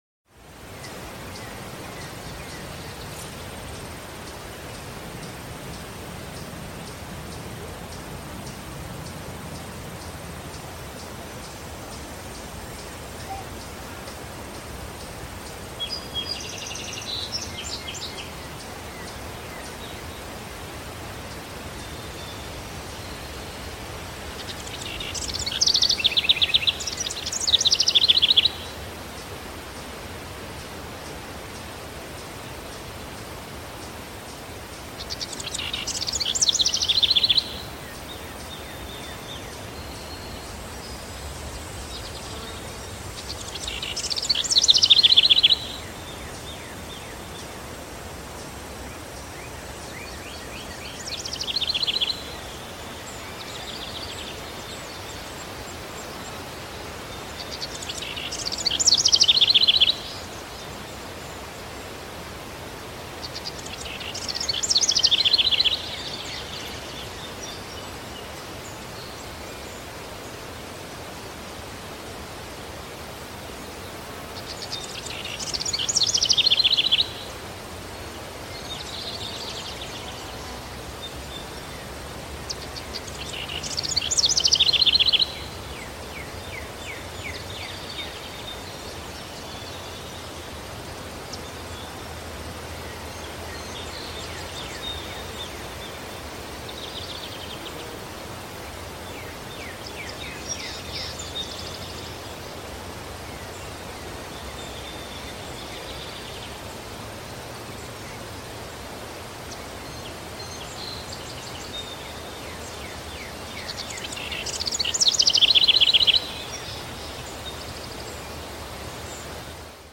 Anthem:
Grass_World_ambience.mp3